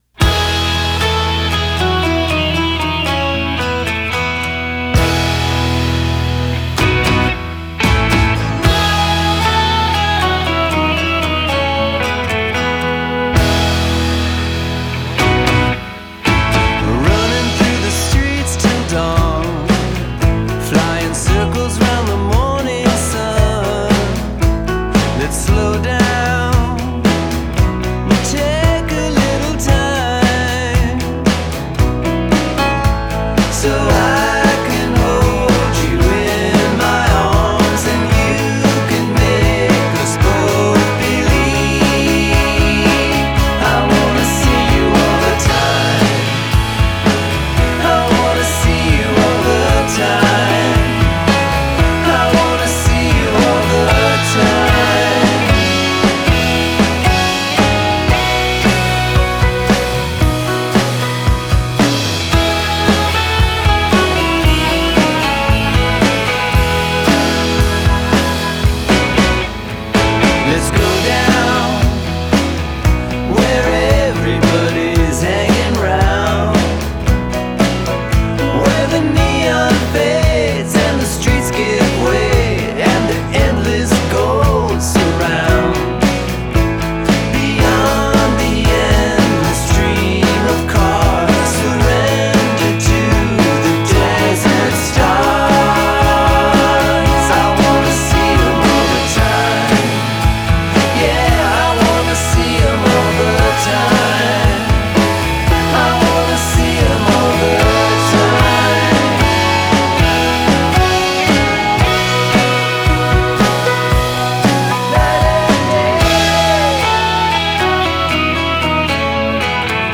the more laidback country/Byrds ‘tude